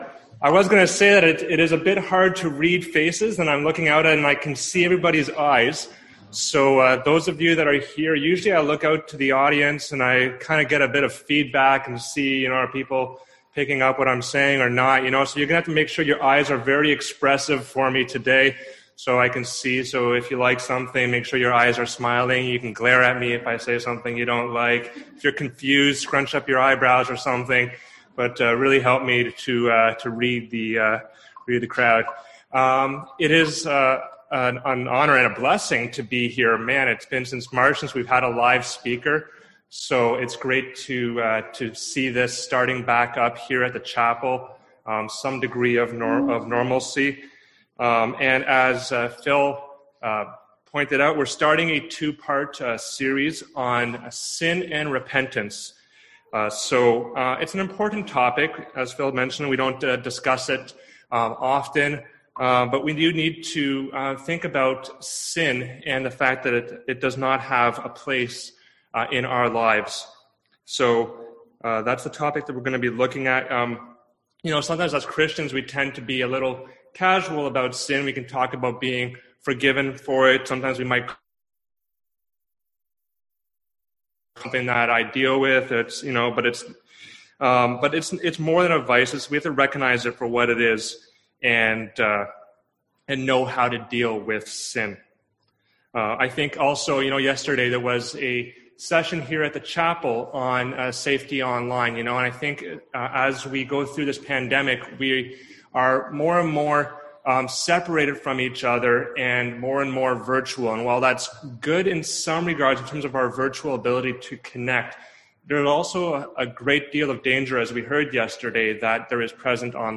Service Type: Sunday AM Topics: Repentance , Sin